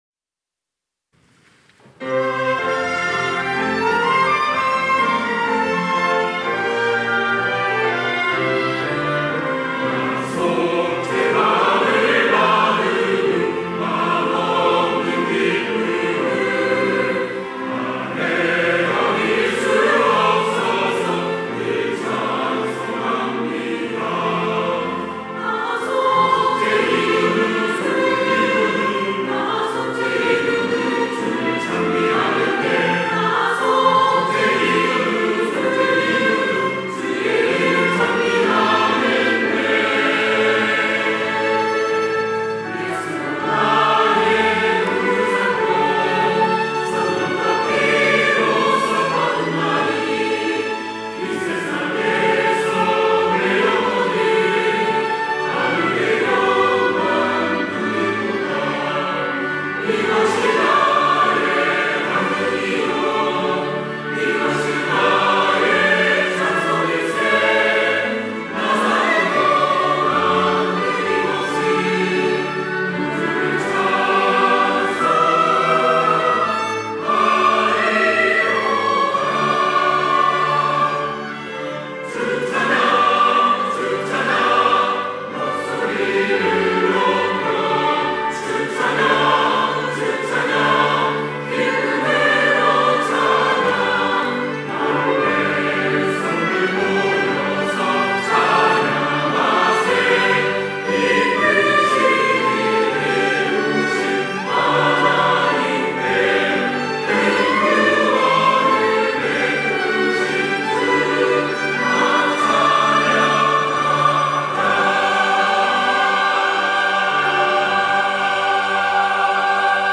호산나(주일3부) - 구원의 찬송
찬양대